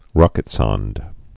(rŏkĭt-sŏnd)